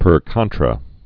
(pər kŏntrə)